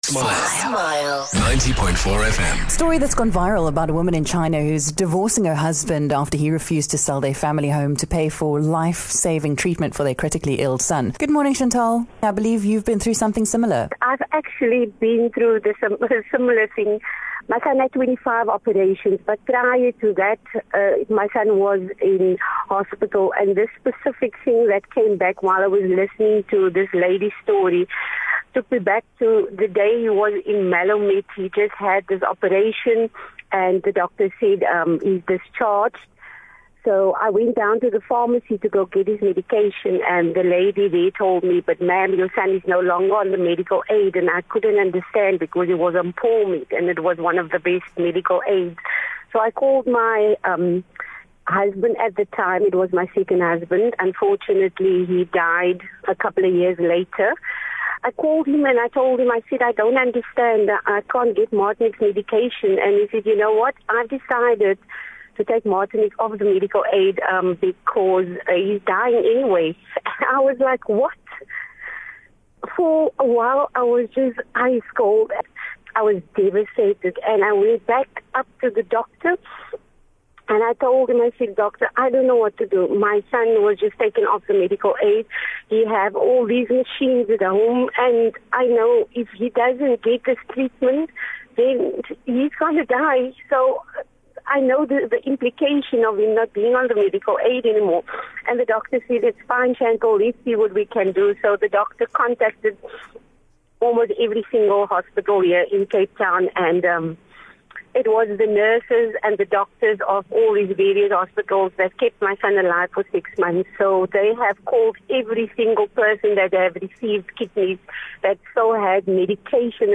The Smile Breakfast team spoke about a viral story of a woman in China who divorced her husband after he refused to sell their home to pay for their son's lifesaving treatment. We got a call from a listener who had been in an almost identical situation.